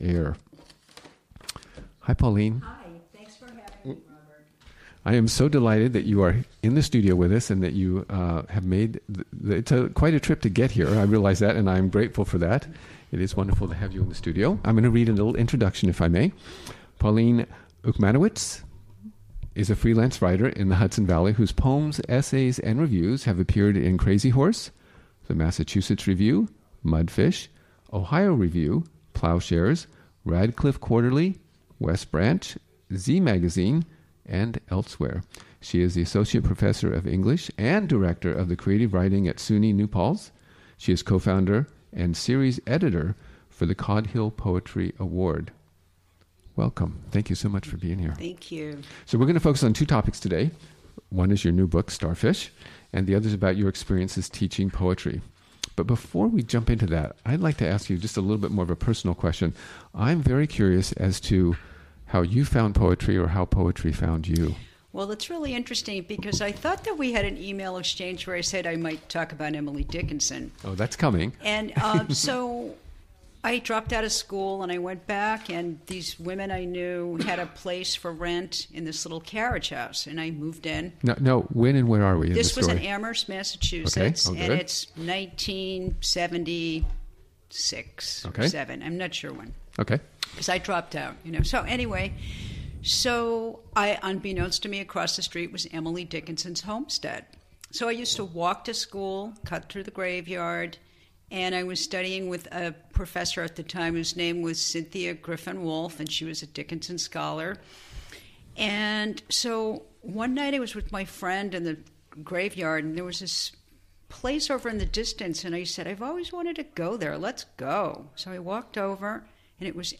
Interviewed
Recorded on the WGXC Afternoon Show on April 18, 2017.